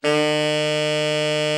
TENOR 8.wav